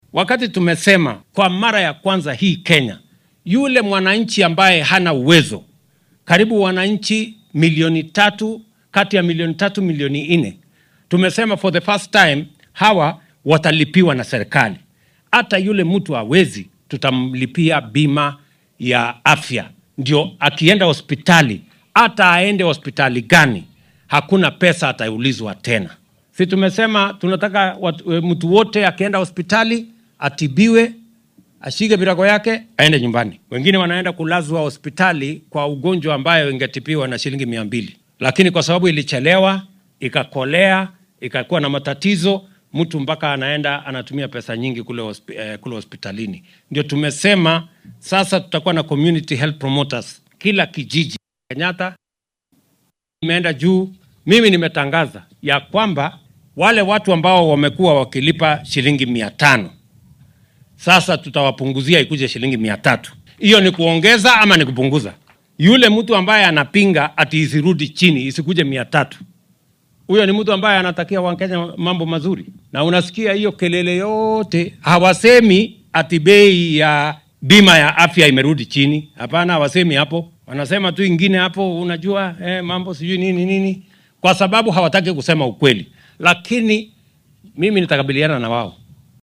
Madaxweynaha dalka William Ruto ayaa dhanka kale ka hadlay arrimaha caafimaadka.